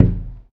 * glass related sounds
* Downmix stereo effects to mono
glass_hit.ogg